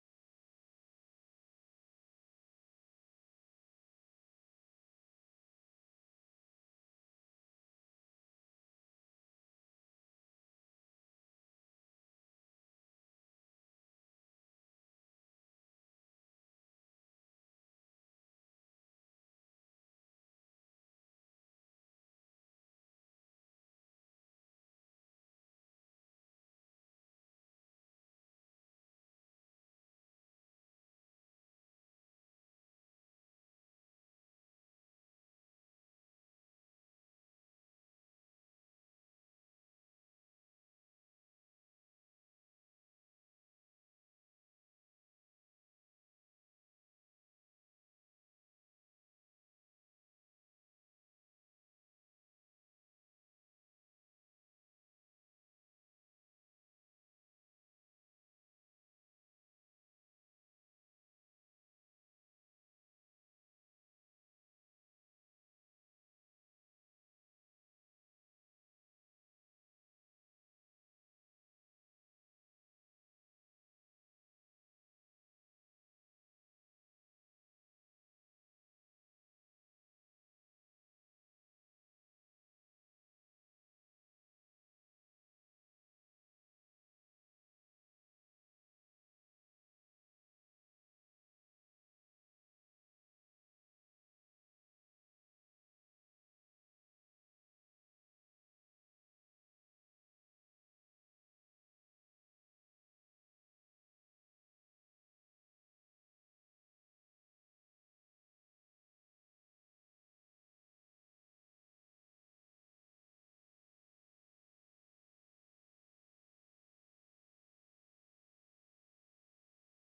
LIVE Morning Worship Service - Pruning Toward Abiding
Congregational singing—of both traditional hymns and newer ones—is typically supported by our pipe organ. Vocal choirs, handbell choirs, small ensembles, instrumentalists, and vocal soloists provide additional music offerings.